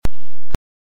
nosound.mp3